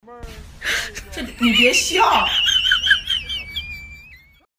抖音你别笑音效_人物音效音效配乐_免费素材下载_提案神器